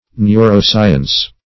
Meaning of neuroscience. neuroscience synonyms, pronunciation, spelling and more from Free Dictionary.